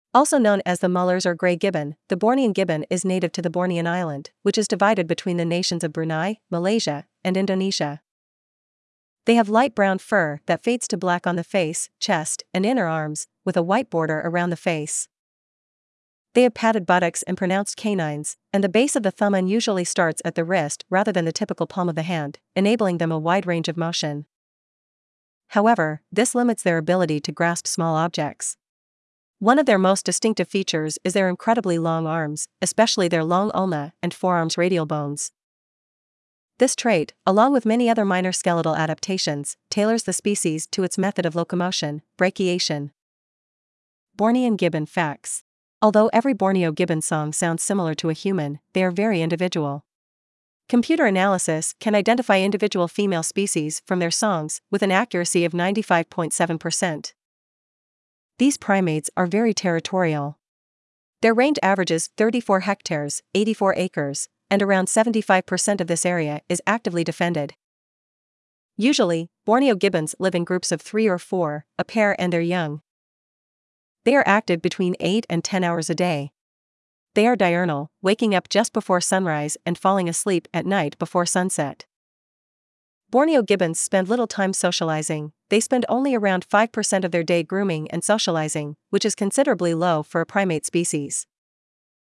Bornean Gibbon
• Although every Borneo gibbon song sounds similar to a human, they are very individual.
Bornean-Gibbon.mp3